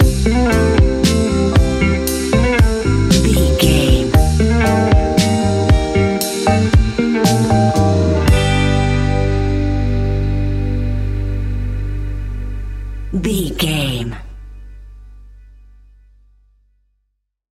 Ionian/Major
laid back
sparse
chilled electronica
ambient
atmospheric
instrumentals